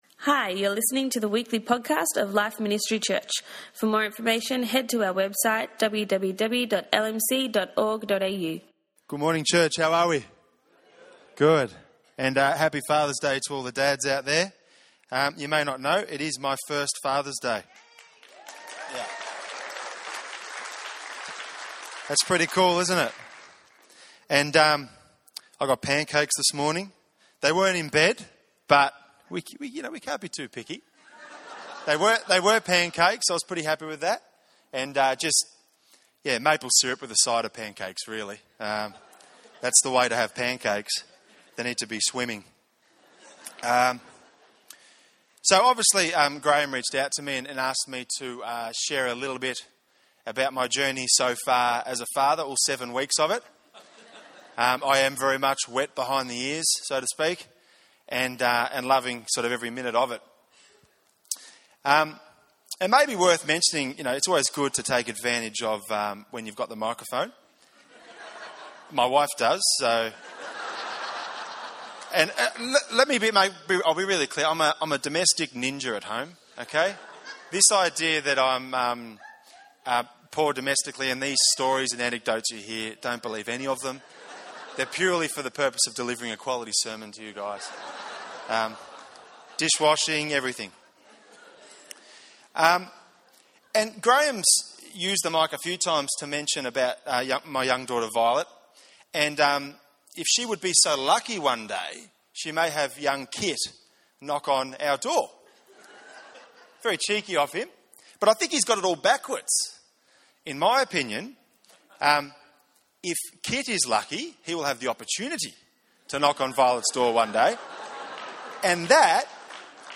It was a wonderful Father's Day service at LMC!